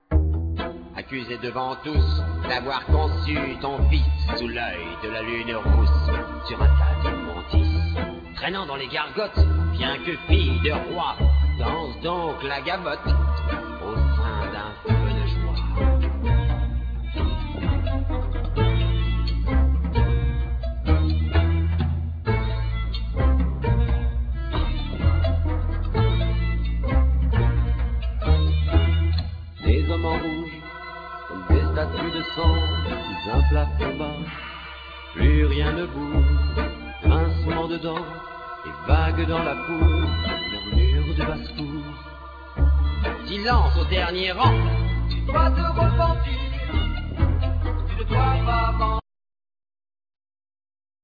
Violin,Viola,Guitar,Vocals
Keyboards,Backing vocals
Drums,Percussions
Ac.Guitar,Bass
English horn
Cello
Flute